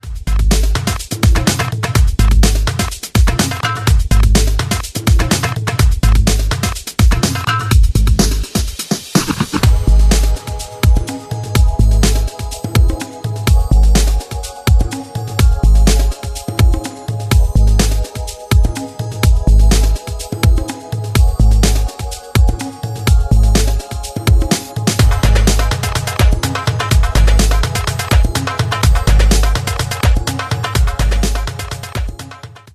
The Summer of Respect - 125 bpm 09.